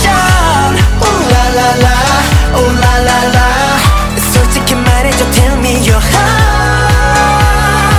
Motown Strings